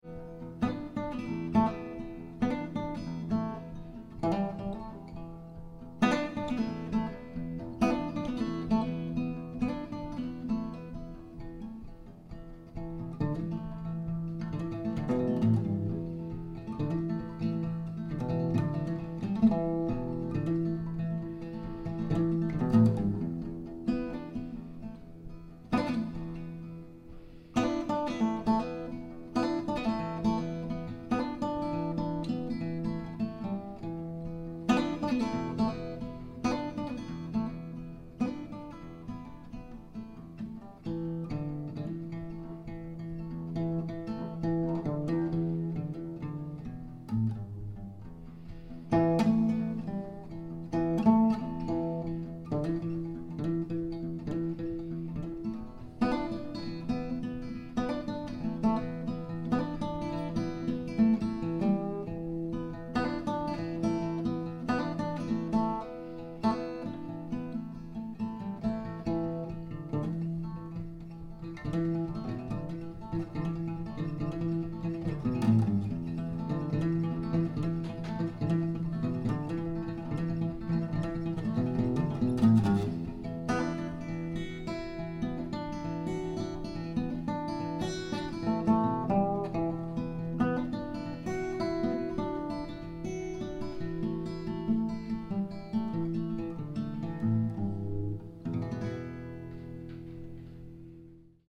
Haunting Drop D Fingerpicking Country Guitar
hauntingly beautiful country fingerpicking instrumental